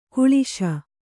♪ kuḷiśa